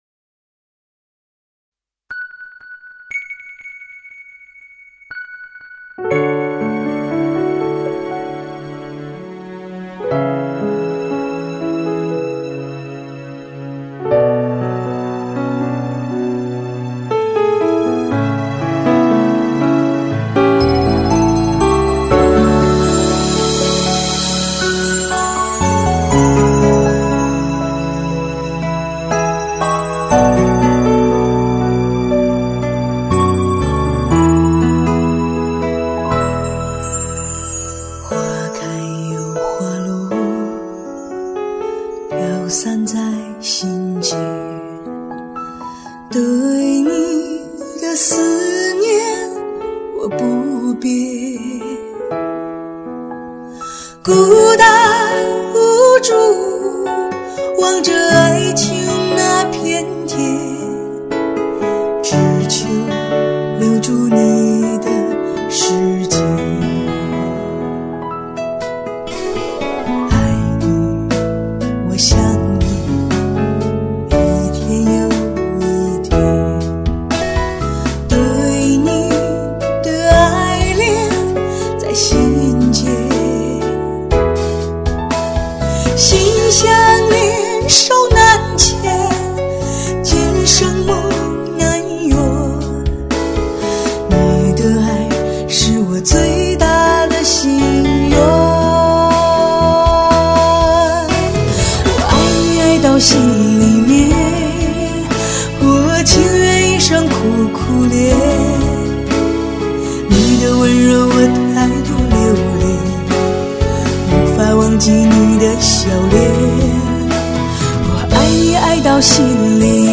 看不透的红尘伤感 唱不尽的无限相思